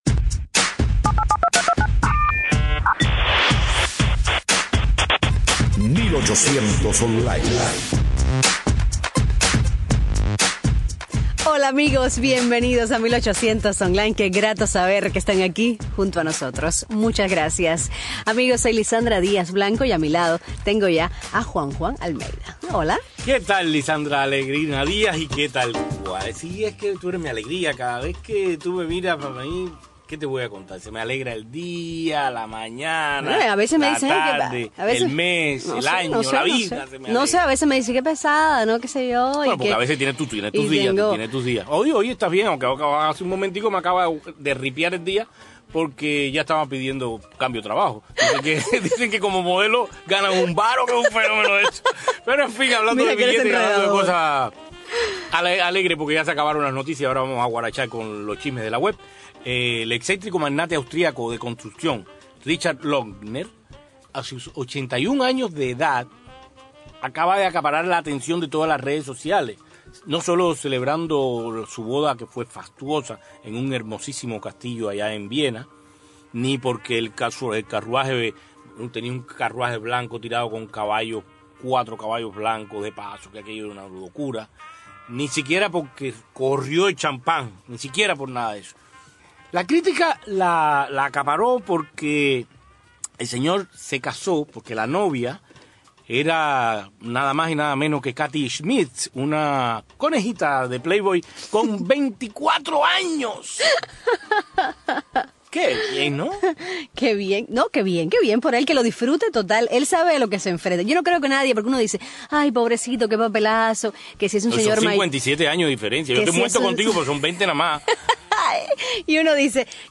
En vivo desde nuestros estudios